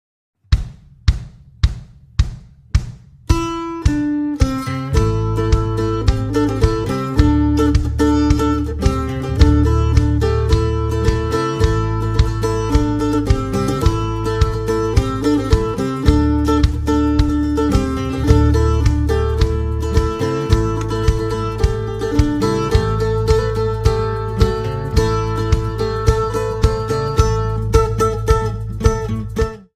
หมวดหมู่: เสียงเรียกเข้า